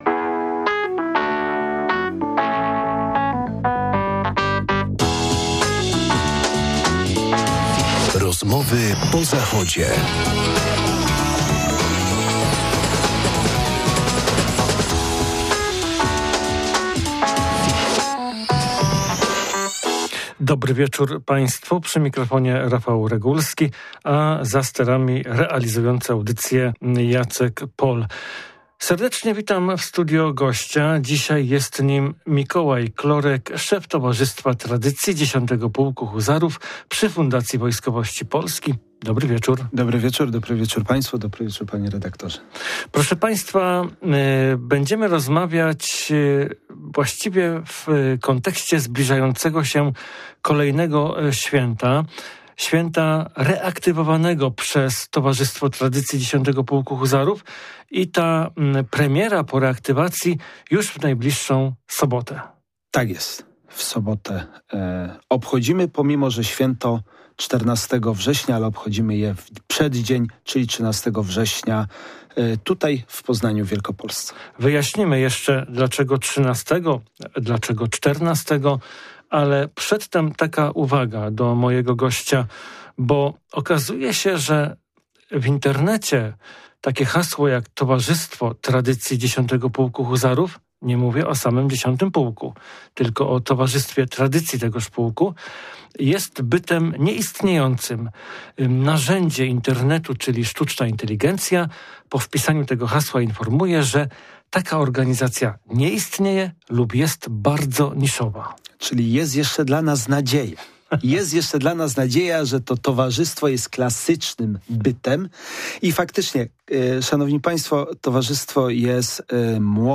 Posłuchajcie rozmowy, w której sporo jest o kawie i 'kasztanach', dowodzących zaradności huzarów z 10 pułku.